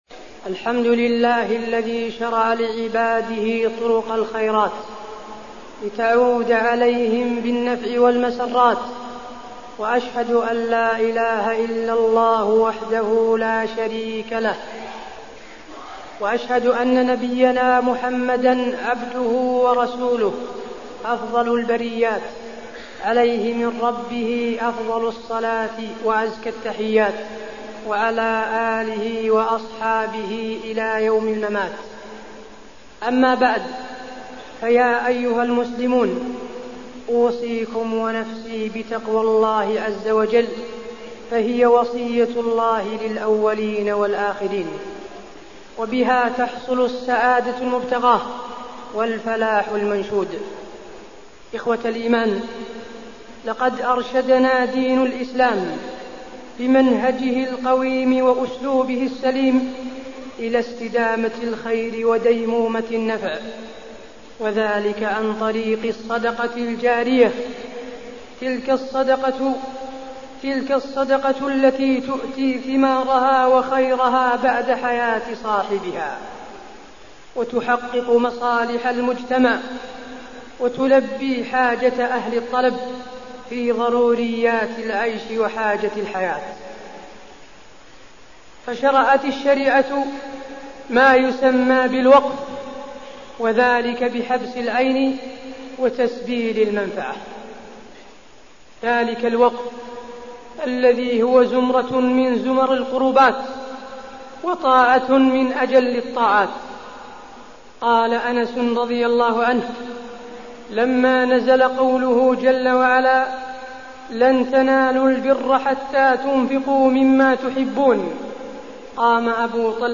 تاريخ النشر ٢٥ ربيع الأول ١٤٢٠ هـ المكان: المسجد النبوي الشيخ: فضيلة الشيخ د. حسين بن عبدالعزيز آل الشيخ فضيلة الشيخ د. حسين بن عبدالعزيز آل الشيخ الوقف